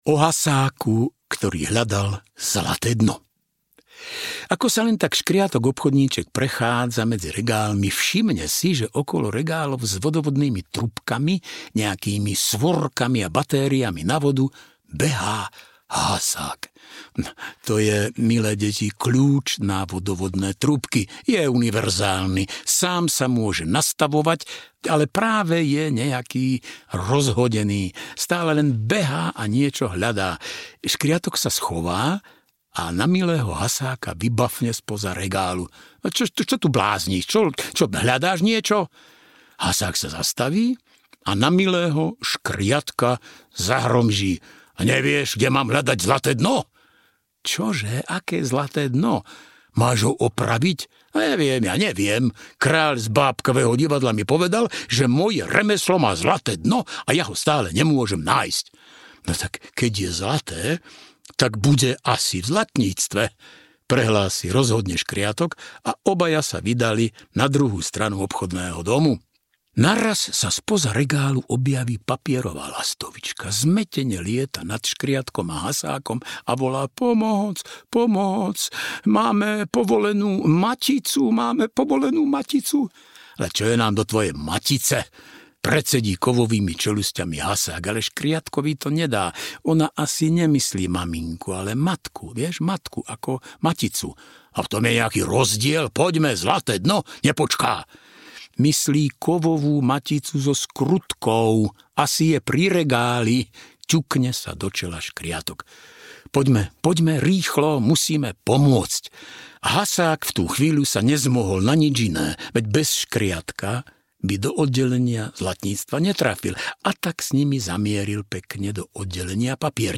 Rozprávky Škriatka Obchodníčka audiokniha
Ukázka z knihy
• InterpretMilan Kňažko